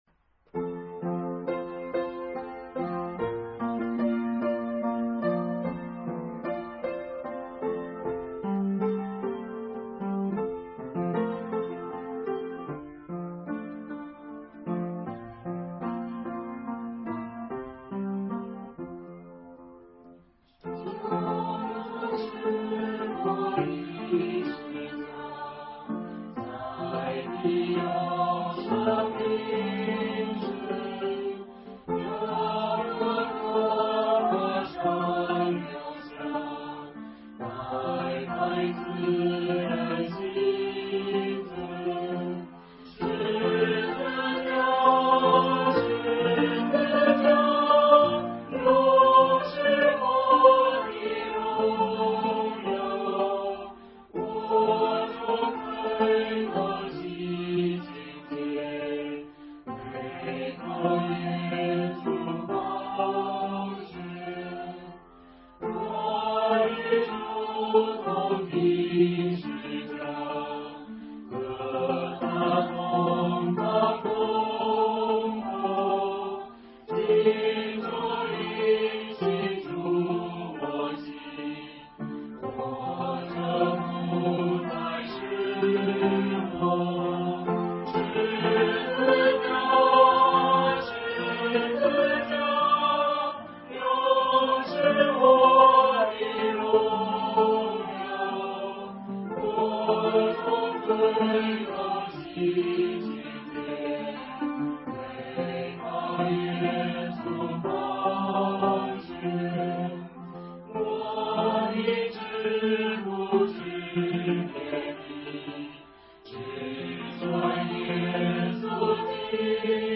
唱诗：求主使我依十架（33，新232）